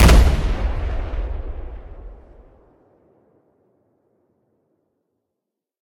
explosion_close.ogg